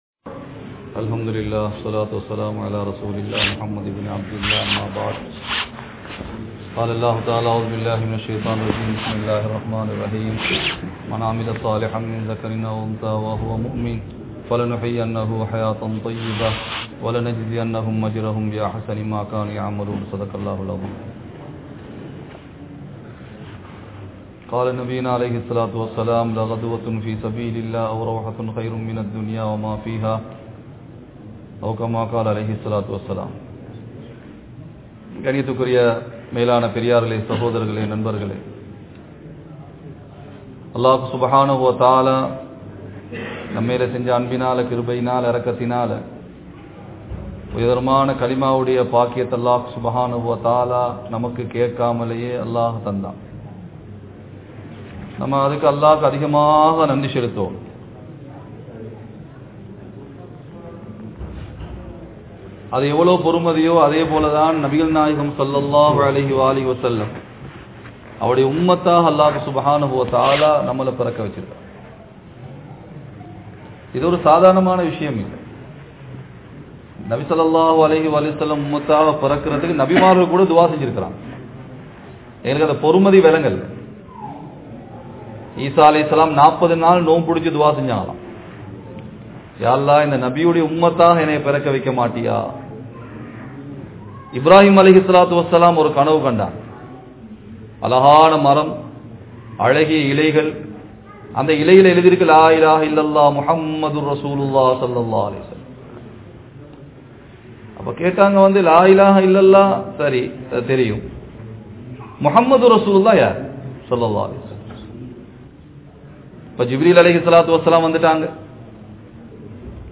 Allah`vin Anpu Ungalukku Veanduma? (அல்லாஹ்வின் அன்பு உங்களுக்கு வேண்டுமா?) | Audio Bayans | All Ceylon Muslim Youth Community | Addalaichenai